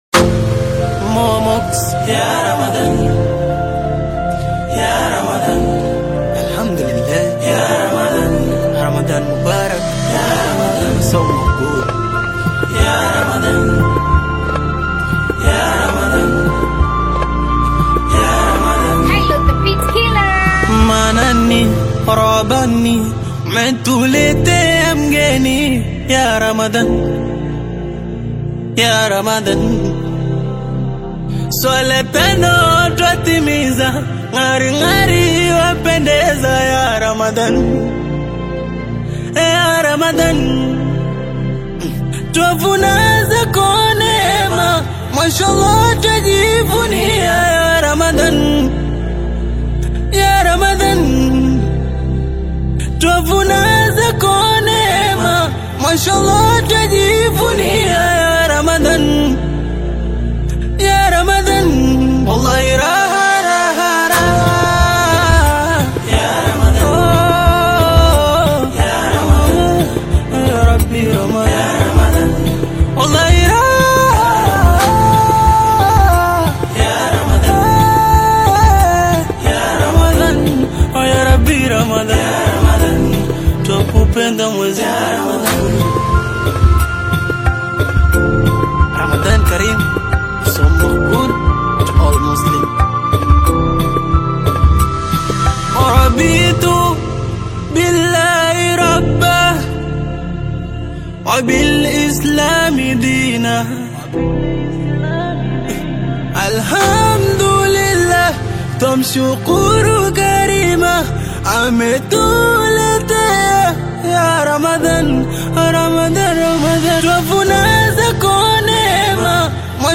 spiritual/Islamic song